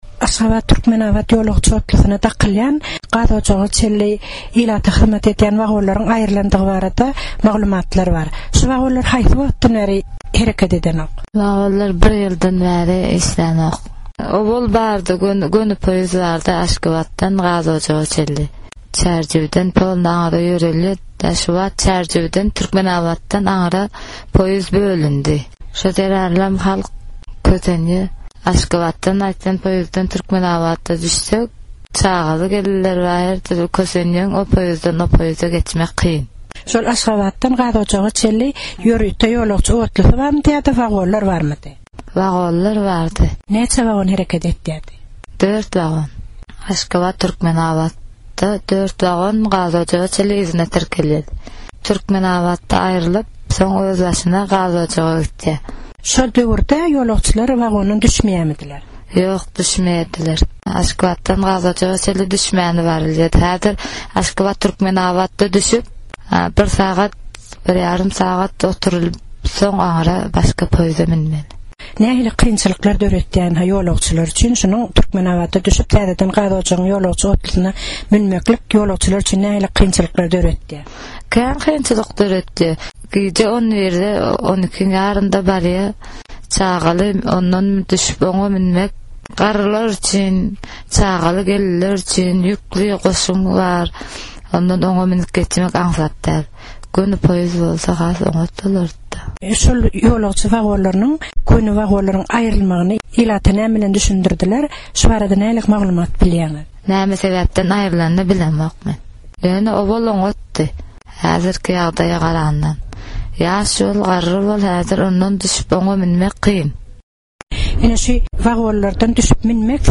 öý hojalykçy zenan bilen söhbetdeş boldy.